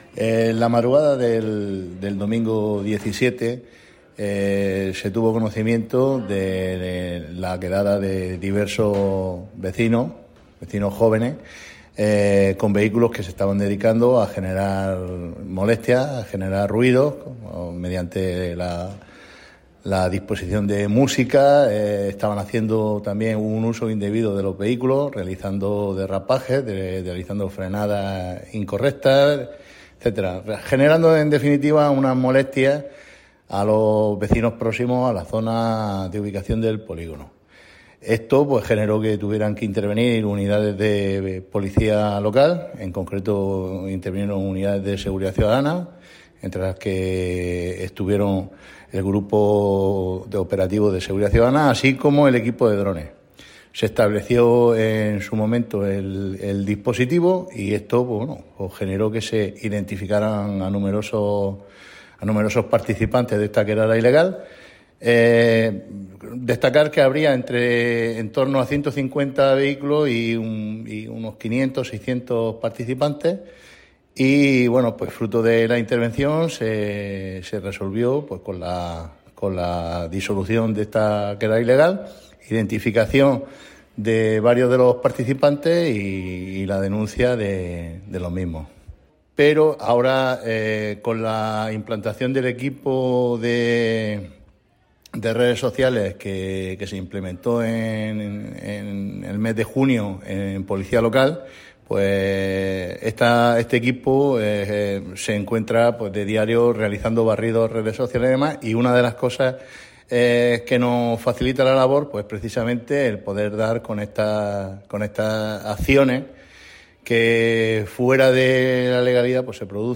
Enlace a Declaraciones del concejal de Seguridad sobre la intervención de la Policía Local en la quedada de coches en Cabezo Beaza